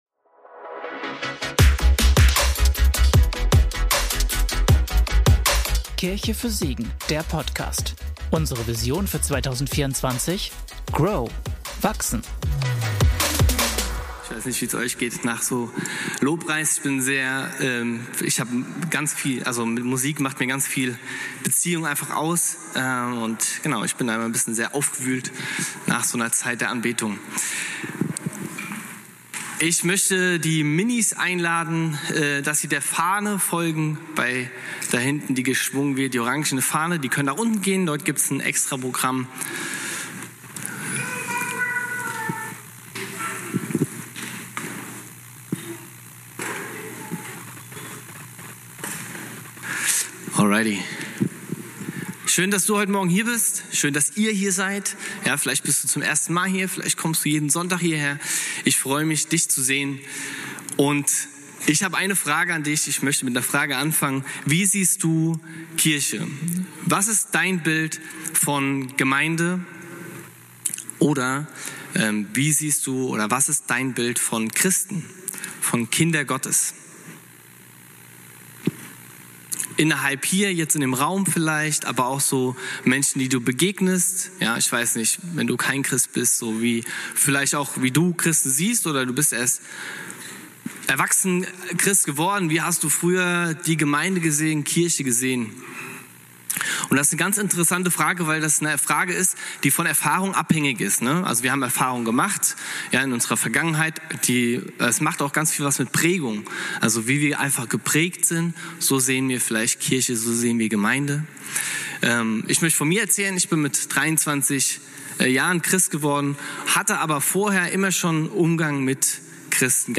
Philipperbrief - Predigtpodcast